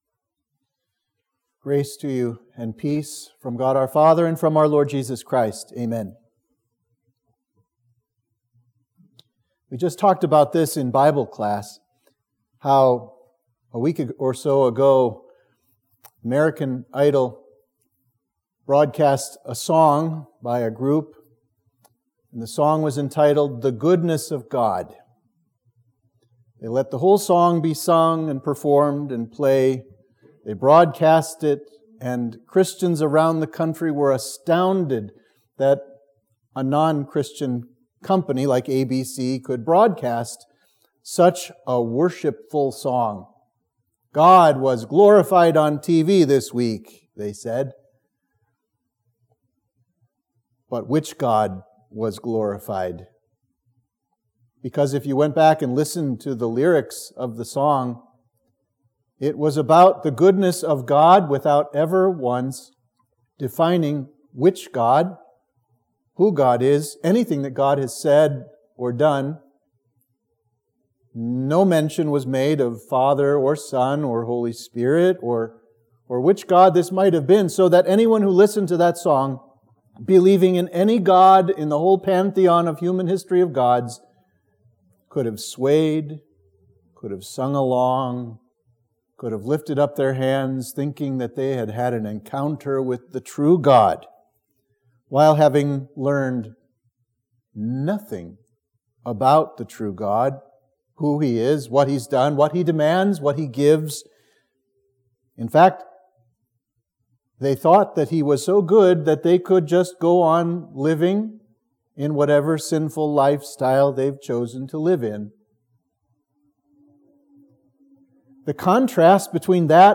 Sermon for Trinity Sunday